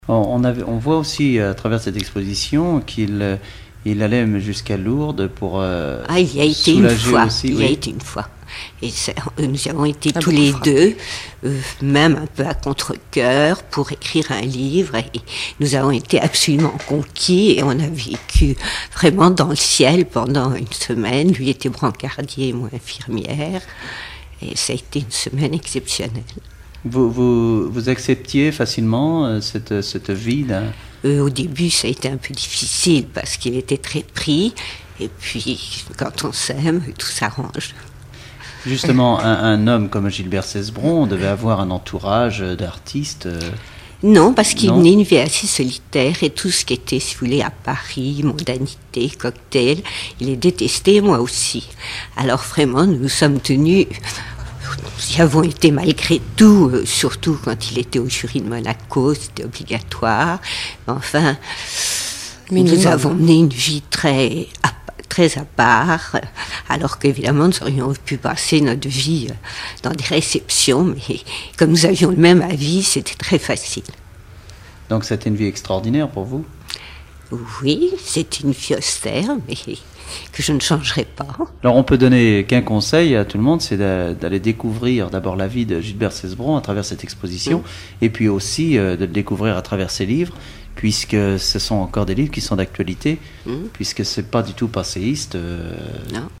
Enquête Alouette FM numérisation d'émissions par EthnoDoc
Catégorie Témoignage